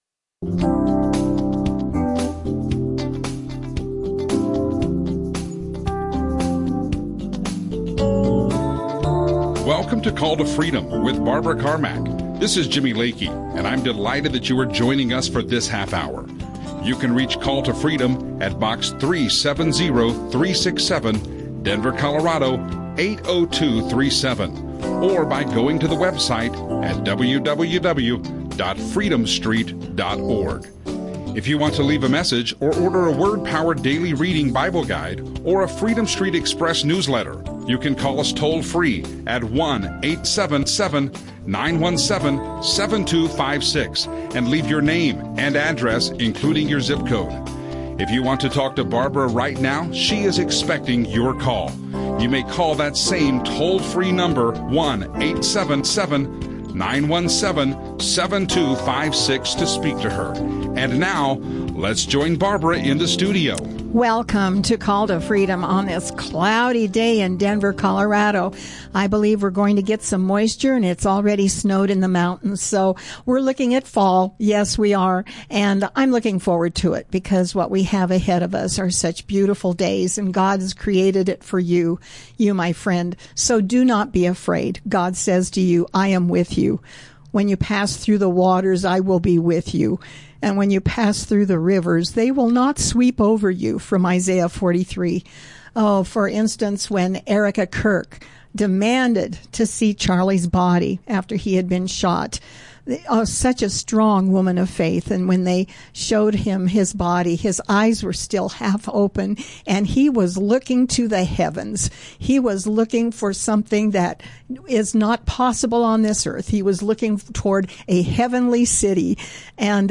Christian talk Conservative Talk